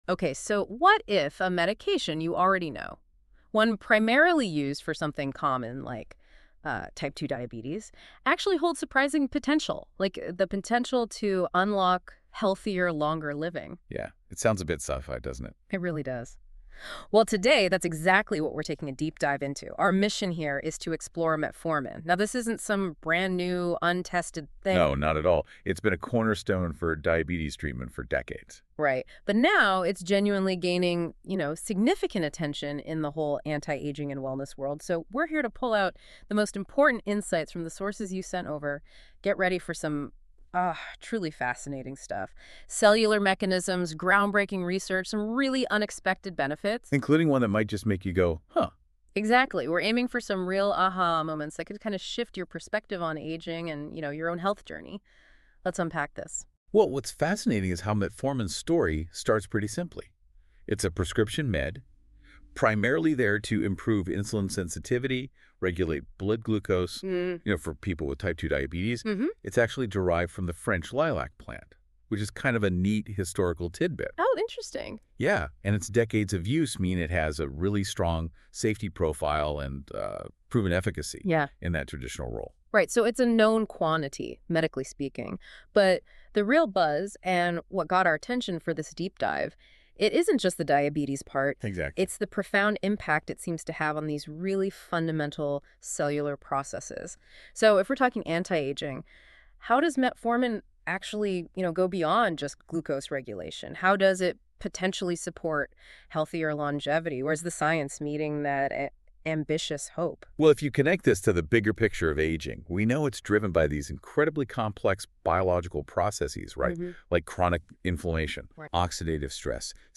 Explore the latest research on metformin and its potential role in healthy aging. Learn how this well-studied medication may support longevity, metabolic health, and overall vitality. Tune in to our expert-led podcast interview for insights on benefits, safety, and what to expect on your wellness journey.